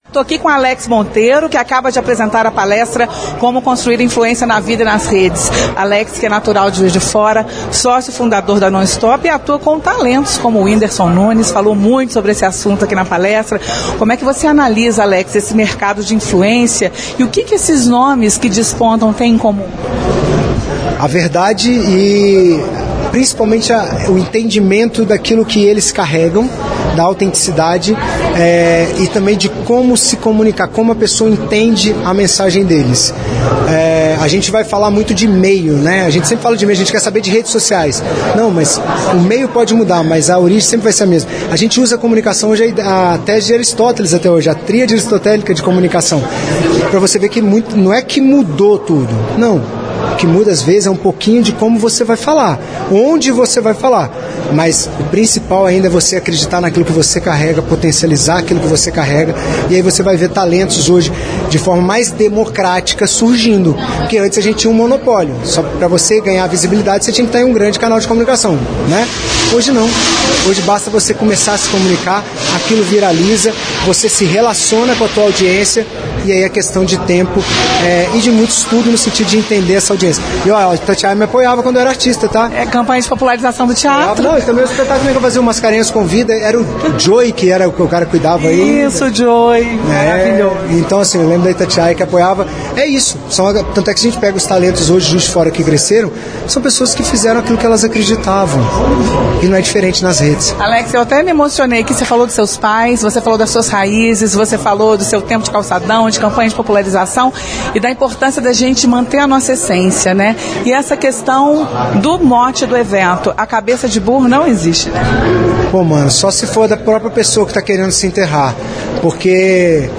A reportagem da Itatiaia acompanhou a primeira tarde do evento e trouxe ao longo da programação as opiniões e avaliações de participantes.